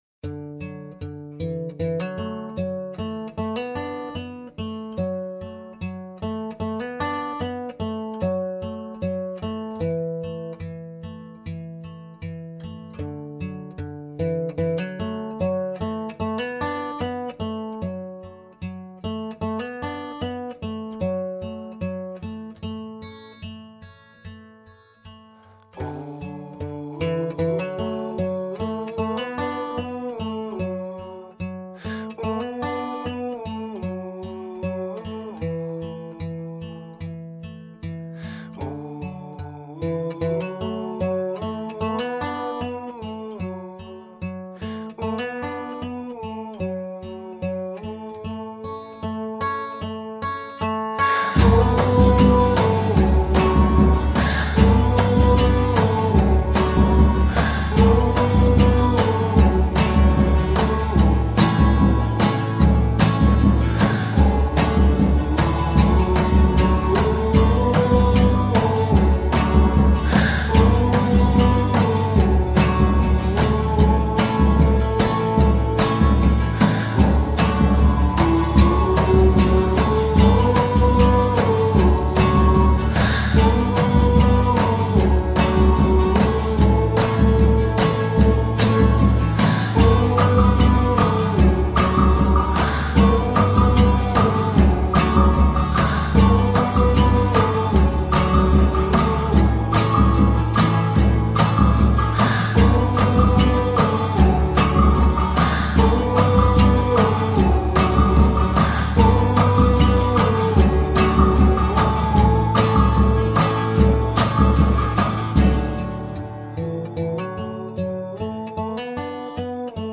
Melody-driven indie-folk.
Tagged as: Alt Rock, Folk-Rock, Chillout, Indie Rock